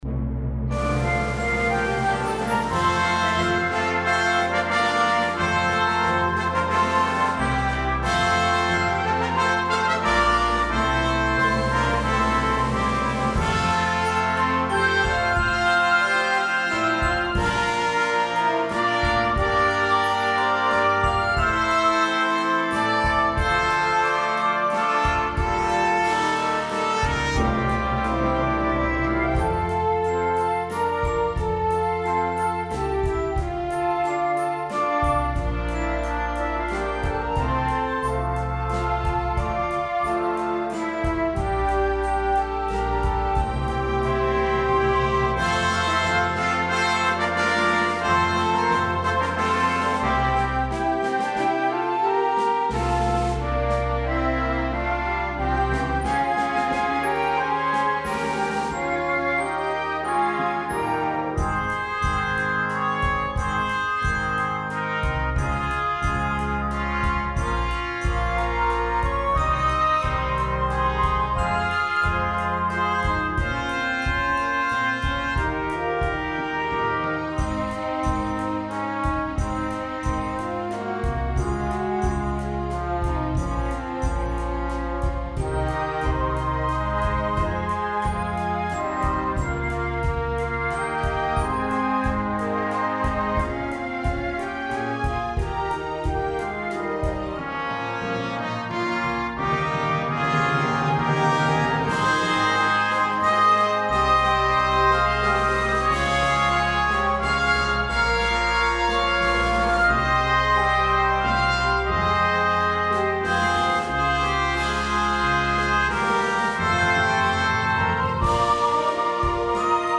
Hymn arrangement
with reduced instrumentation.
Playable with as few as 9 Players.